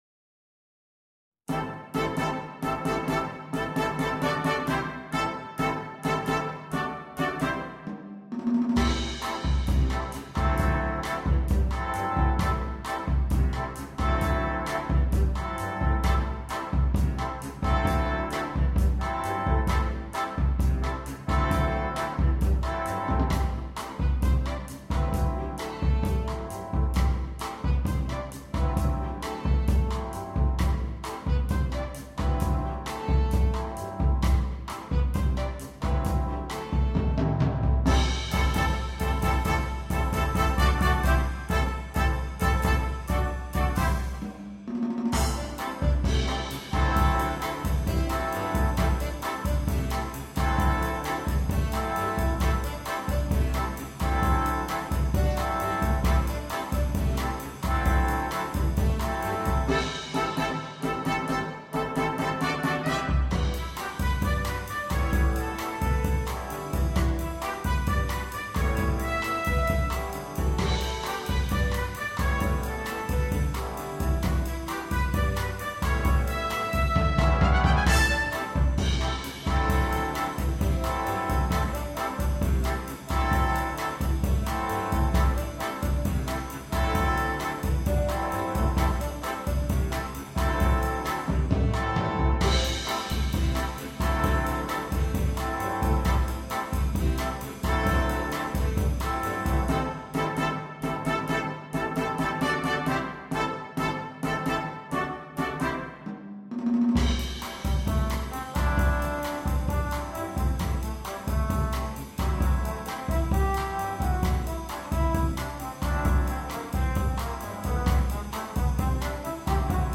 для джаз-бэнда.